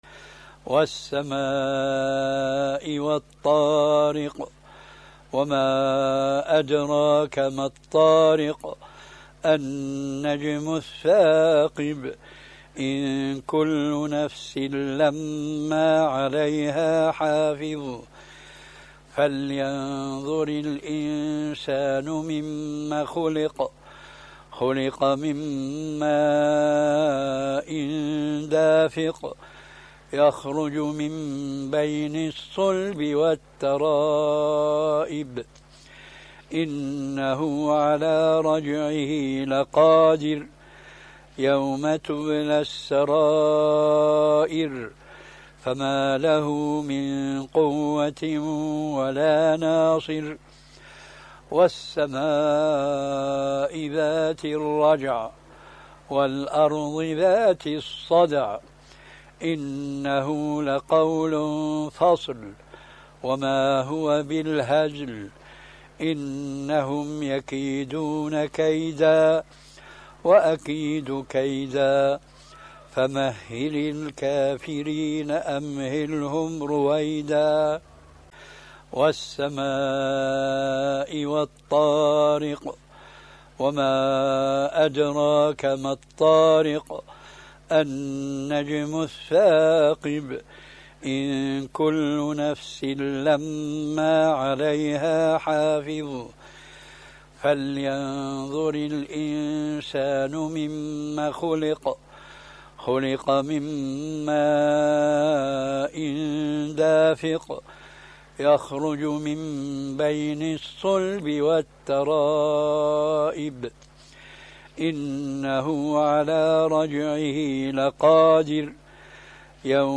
الطارق القارئ: فضيلة الشيخ محمد ناصر الدين الألباني الصنف: تلاوات تاريخ: الأحد 14 رجب 1436 هـ الموافق لـ : 3 ماي 2015 م رواية : حفص عن عاصم الحجم:1.0M المدة :00:01:18 حمله :255 سمعه :446 سماع التلاوة تحميل التلاوة